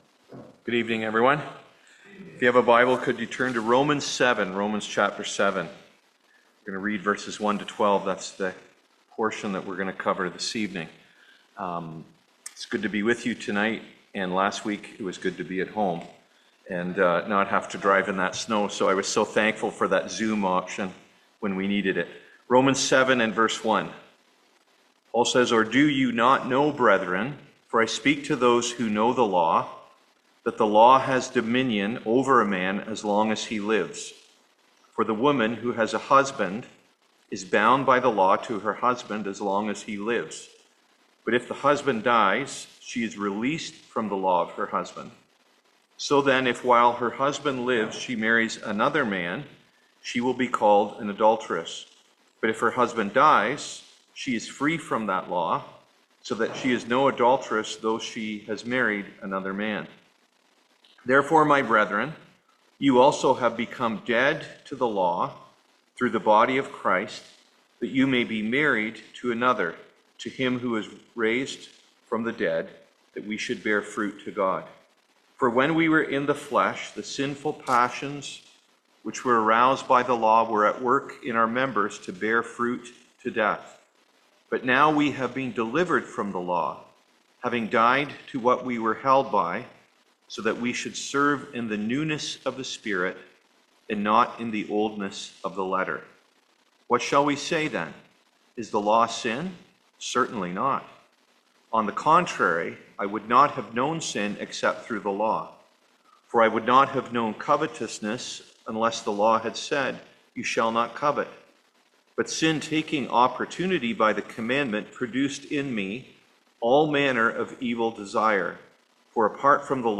Service Type: Seminar